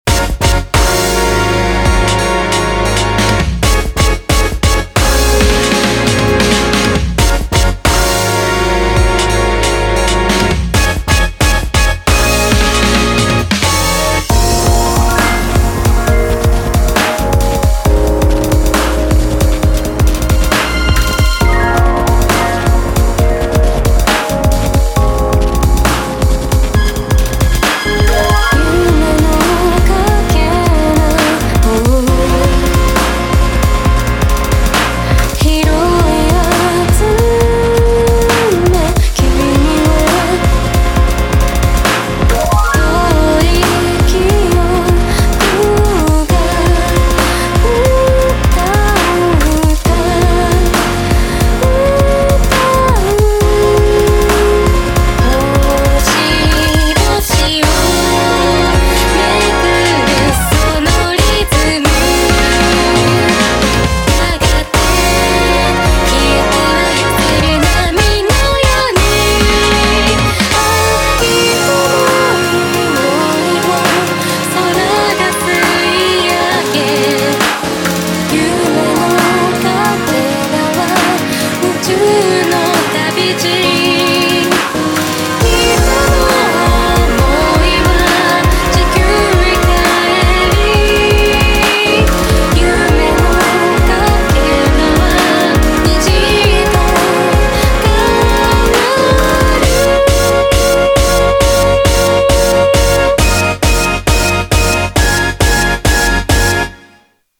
BPM68-135
Audio QualityPerfect (High Quality)
Comments[SYMPHONIC HOUSE]